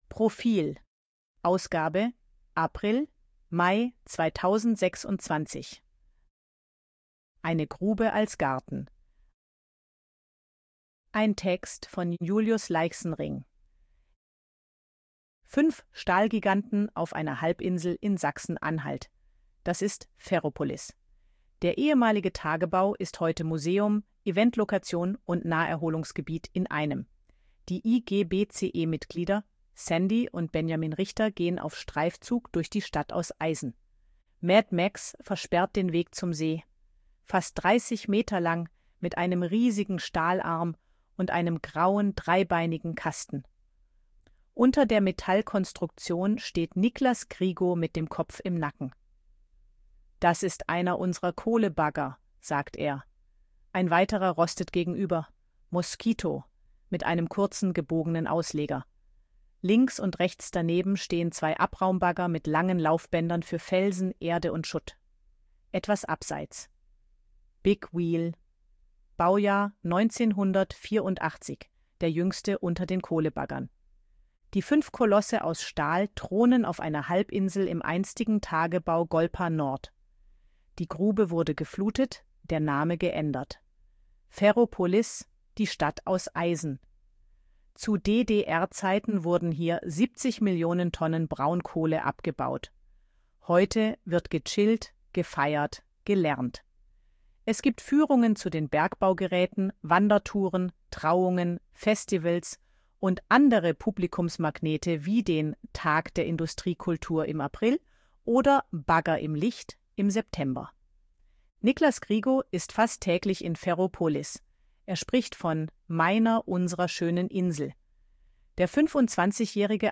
Artikel von KI vorlesen lassen
ElevenLabs_262_KI_Stimme_Frau_Betriebsausflug.ogg